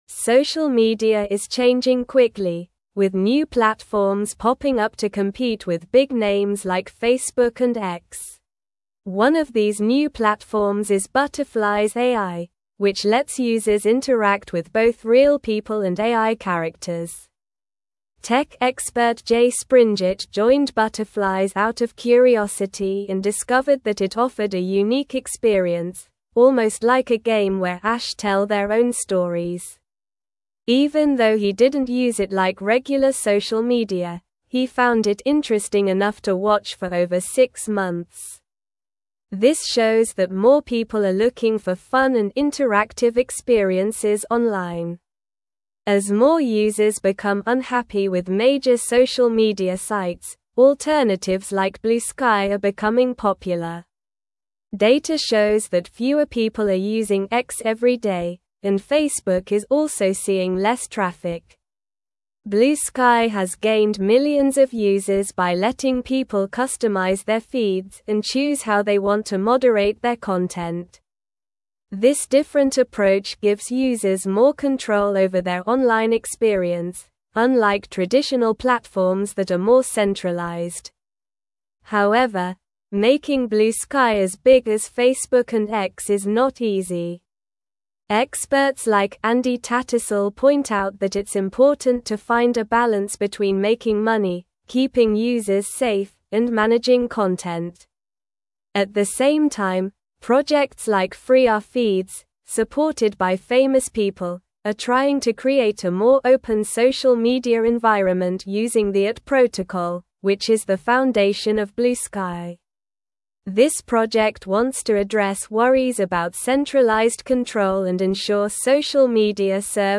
Slow
English-Newsroom-Upper-Intermediate-SLOW-Reading-Emerging-Social-Media-Platforms-Challenge-Established-Giants.mp3